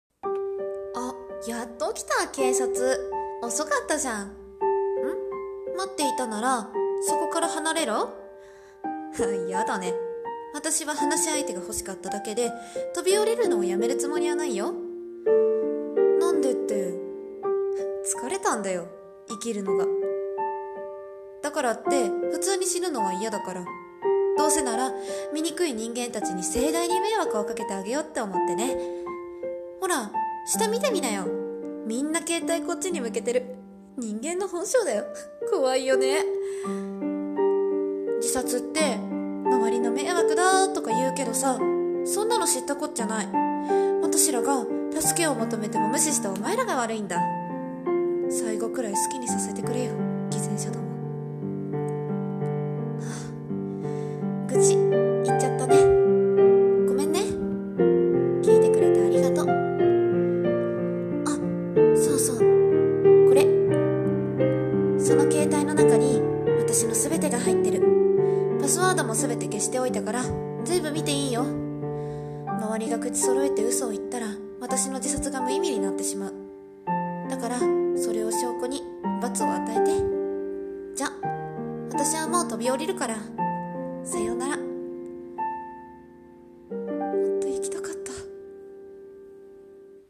【声劇・台本】ジサツ